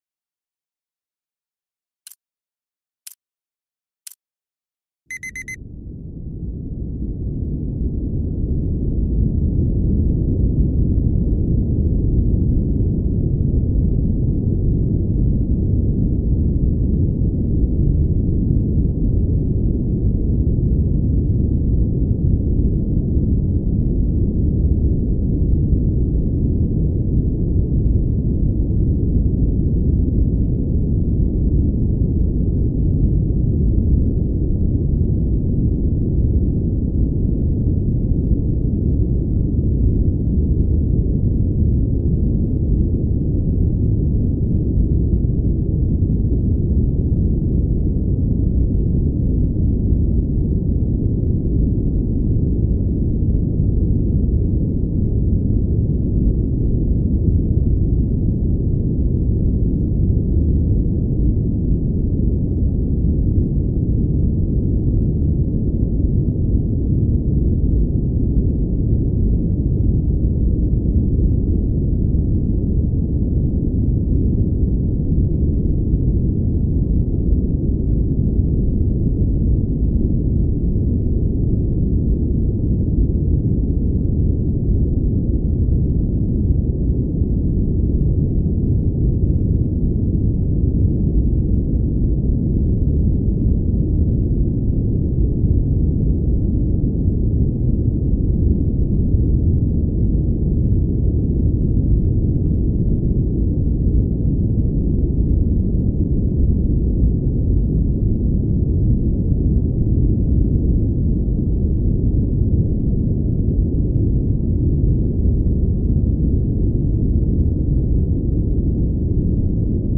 【集中力アップ】ポモドーロタイマー×ブラウンノイズ 30分×5分休憩
私たちが届けるのは、ホワイトノイズ、Brown Noise、そしてAmbient Noiseの間を彷徨う音の彫刻。